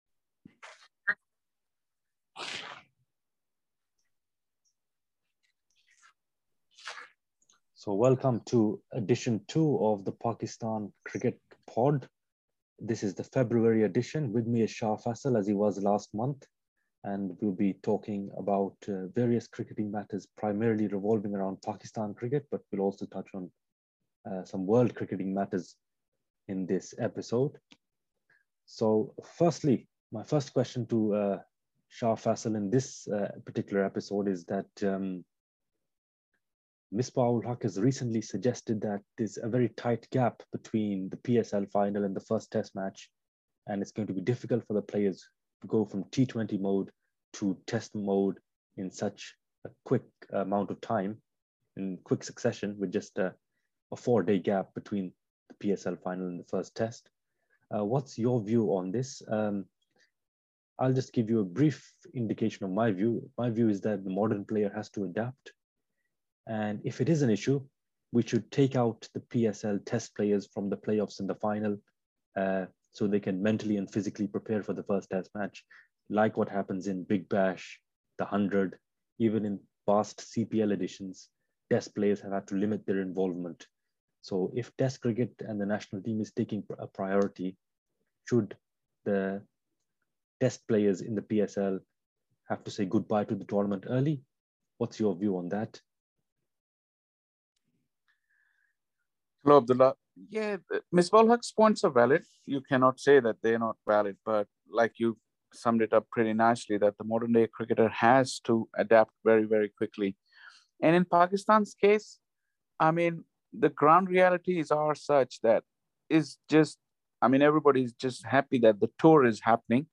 Cricket chat.
Note: The audio attached above is quiet just for the first 7 seconds.